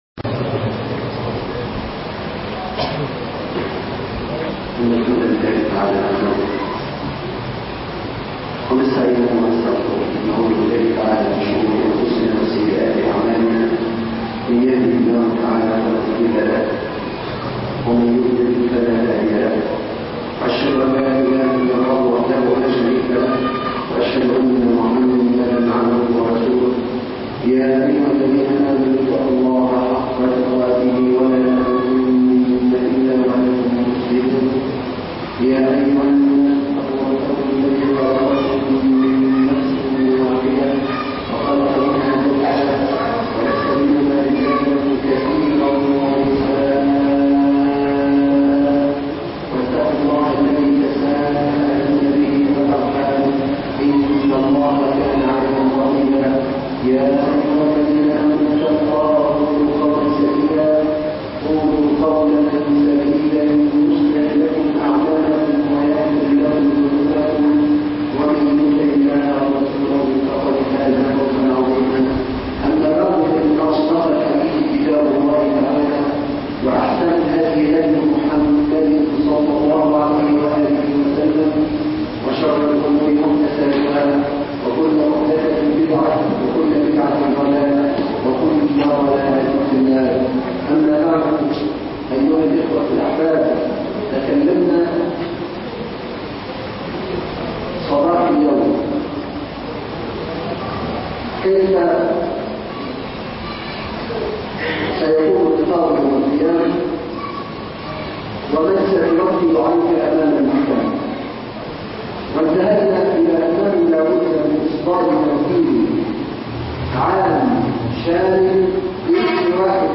محكمة العدل 2 (دروس وخطب من المساجد